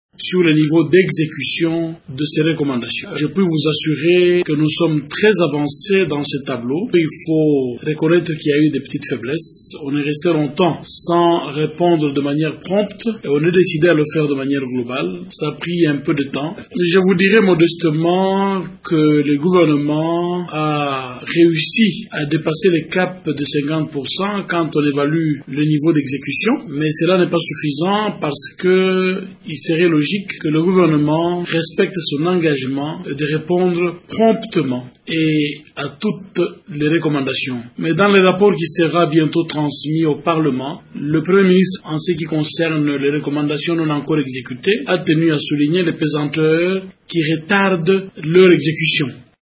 Le ministre en charge les Relations avec le Parlement l’a affirmé ce mardi à Kinshasa au cours d’une conférence de presse.
Il l’a souligné dans cet extrait recueilli par radio Okapi: